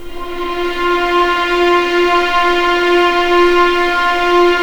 F4LEGPVLN  L.wav